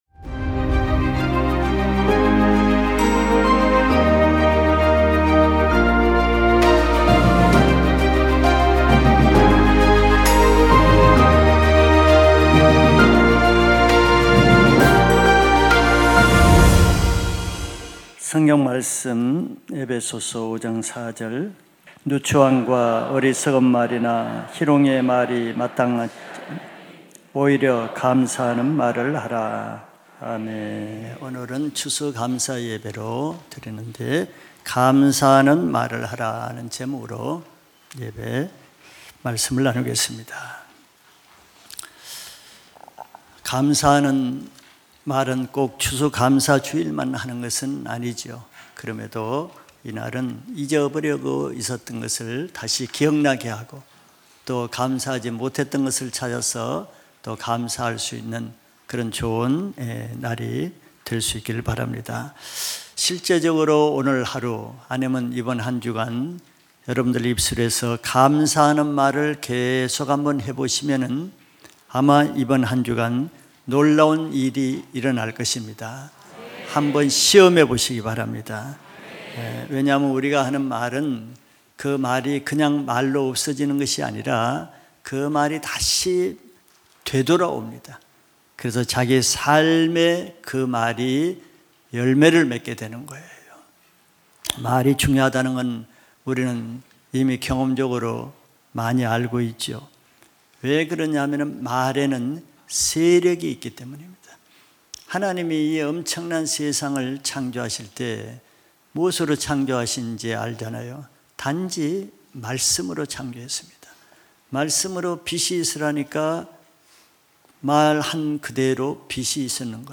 2025년9월28일 주일예배말씀